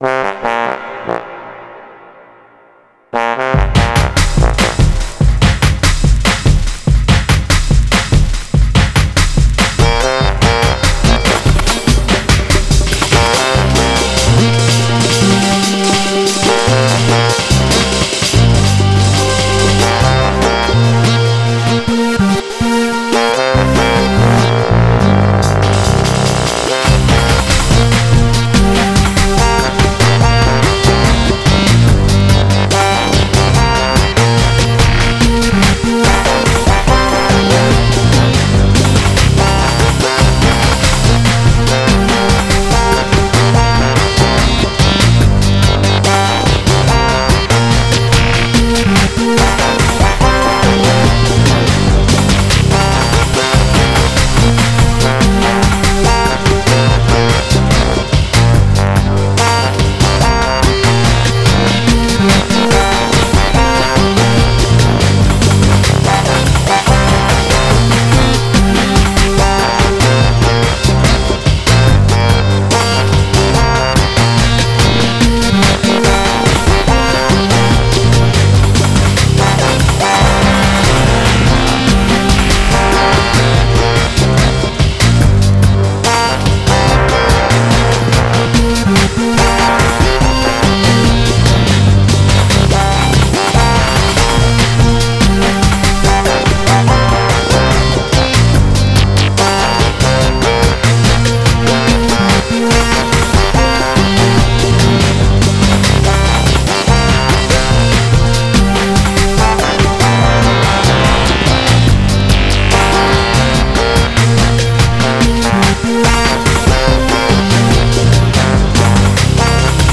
Categorised in: rhythmic, urgent